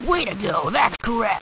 描述：播音员说“走的路，这是正确的！”，听起来像是来自声卡。